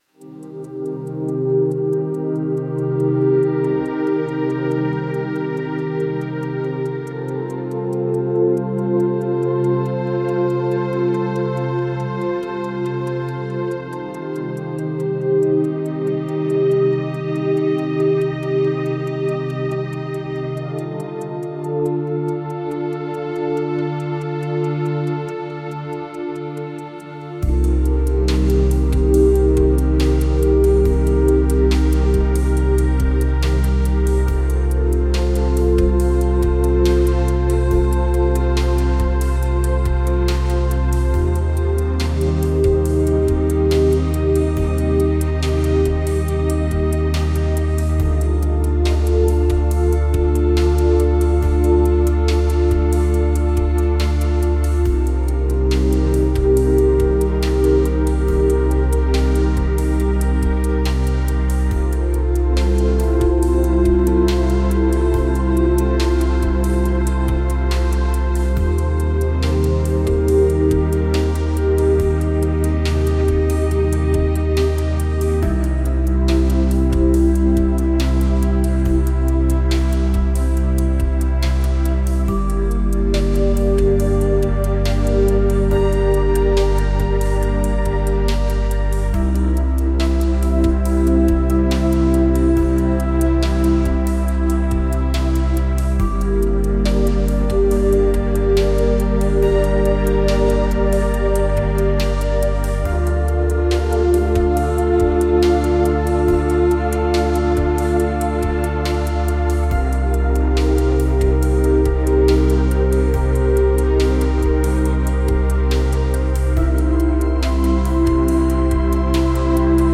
专注于器乐创作，Stable Audio能够生成长达三分钟的高质量音乐曲目。这些曲目以44.1kHz立体声标准生成，Stable Audio2.0版本是在AudioSparx音乐库的授权数据集上训练而成。